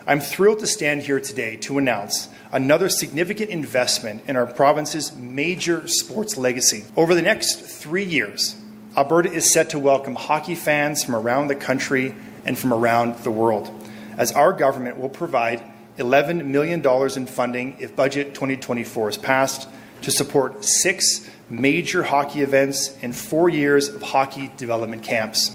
Minister of Tourism and Sport Joseph Schow says it’s a significant investment in the provinces sports legacy, with 11 million going towards the events that will be held within Alberta.